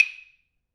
Clave Groovin.wav